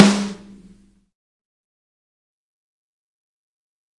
天生的陷阱
描述：来自太平洋鼓槌的自然圈套.14X4.5 一定声音
Tag: 谐波 小鼓 声学